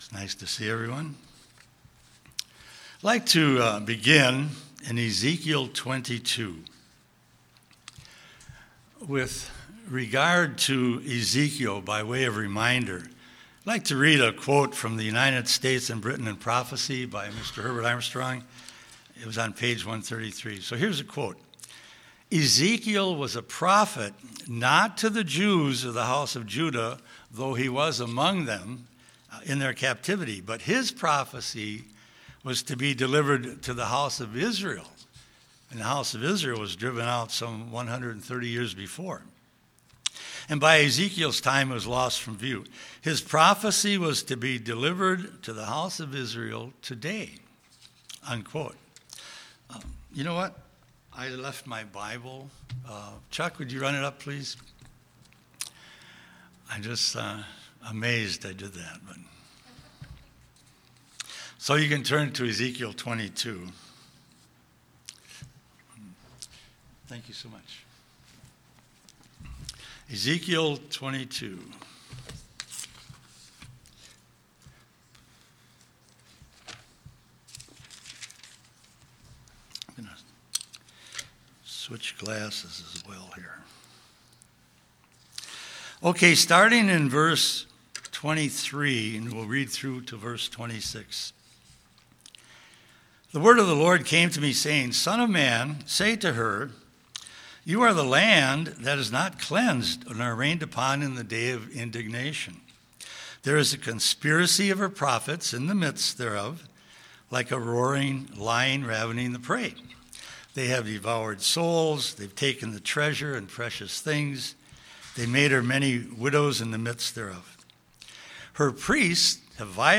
Sermons
Given in Twin Cities, MN